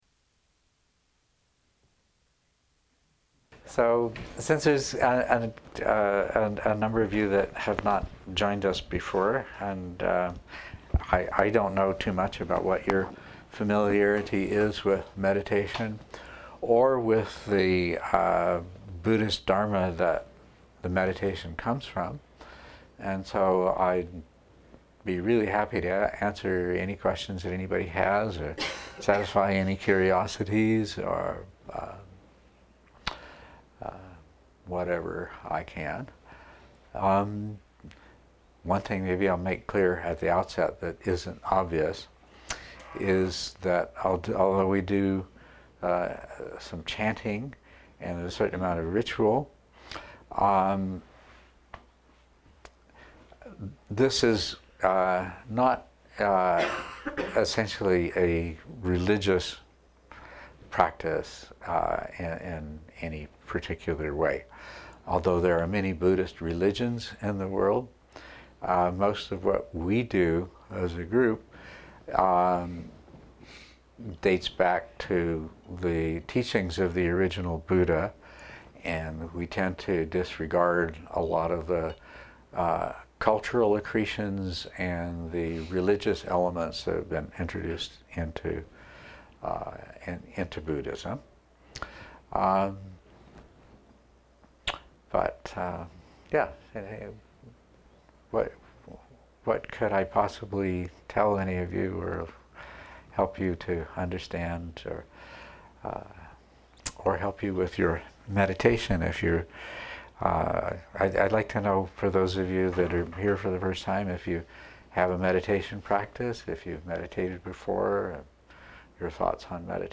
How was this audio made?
MP3 of the Dharma talk at the Stronghold